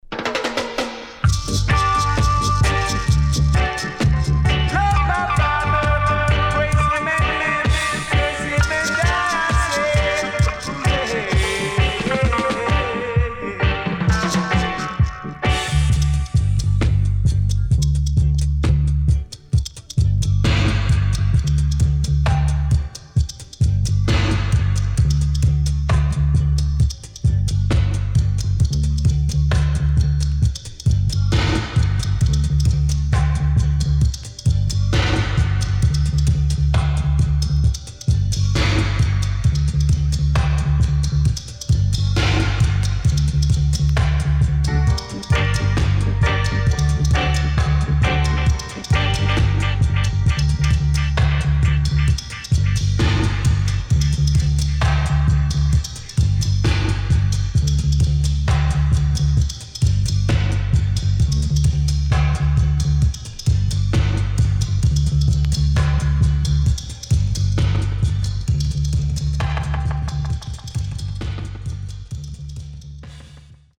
HOME > REISSUE [REGGAE / ROOTS]
【12inch】